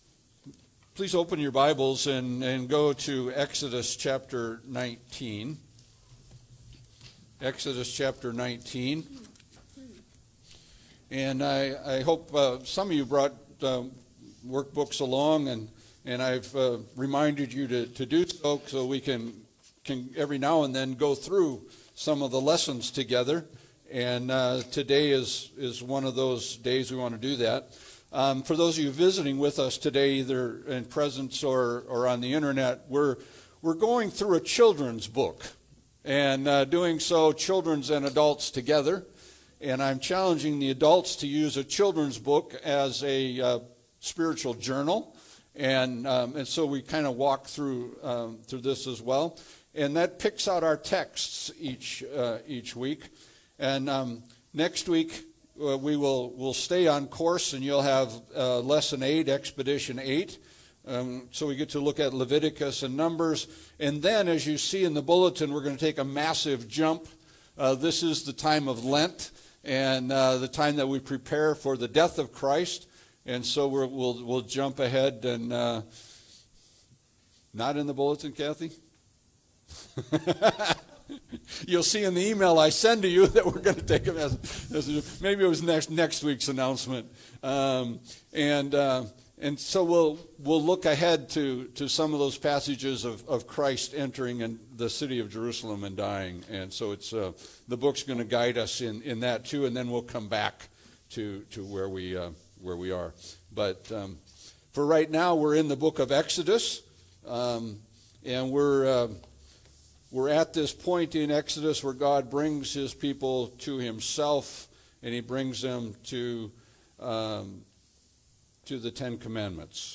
Passage: Exodus 19:1-6 Service Type: Sunday Service